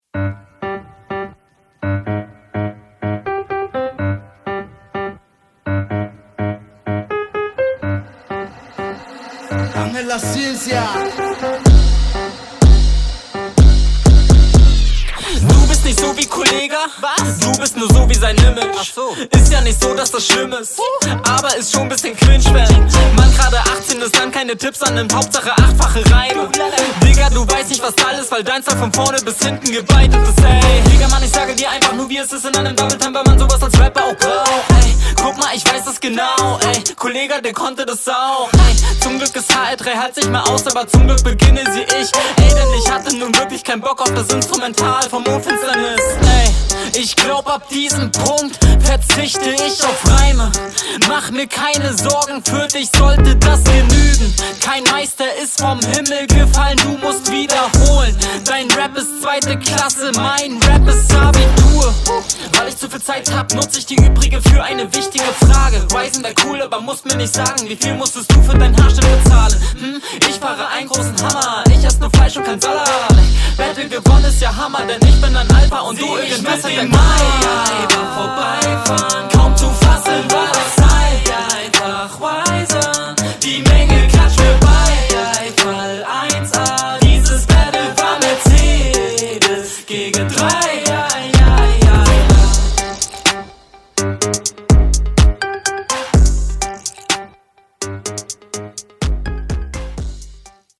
Beat ist geil. Auch die Spielereien mit den Adlips ist n schönes Ding.